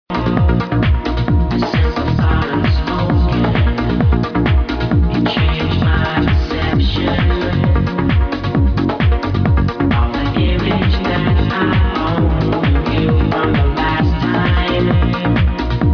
It sounds like they had something mixed in with it as well.